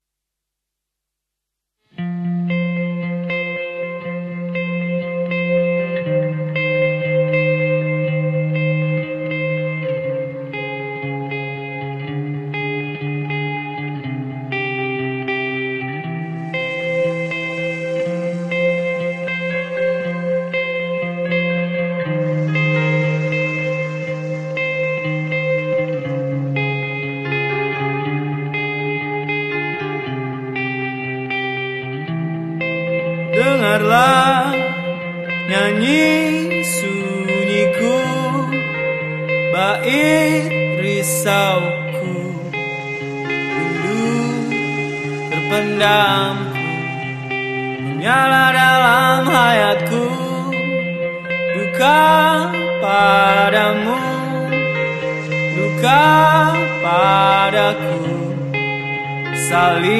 Suasana kawasan Mako Brimob Kwitang, sound effects free download
Suasana kawasan Mako Brimob Kwitang, Jakarta Pusat kini sudah kondusif. Massa yang sempat berkerumun melakukan aksi hingga ricuh sudah tidak tampak, lalu lintas di lokasi ramai lancar.